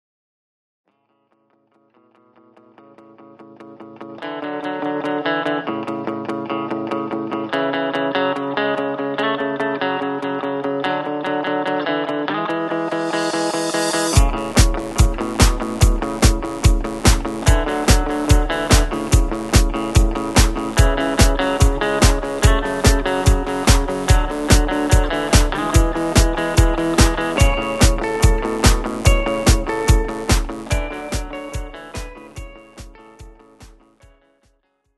This is an instrumental backing track cover.
• Key – A
• With Backing Vocals
• No Fade